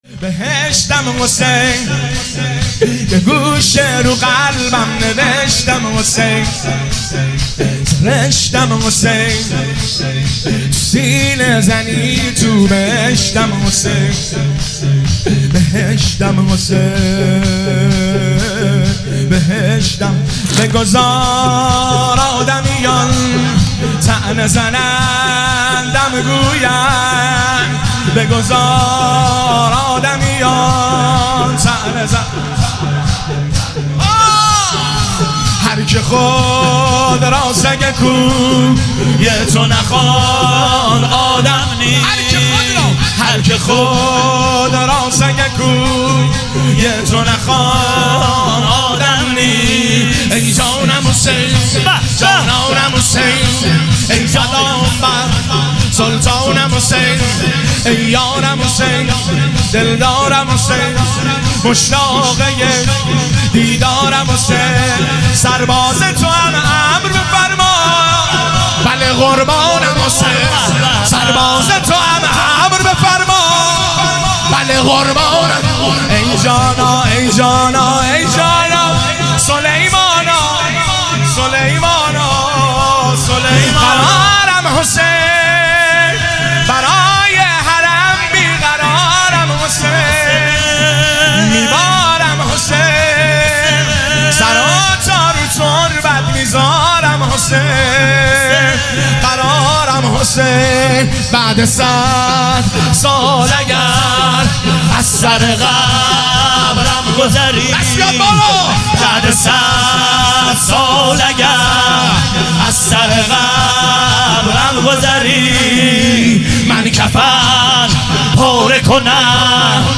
هیئت ام المصائب (س) بابل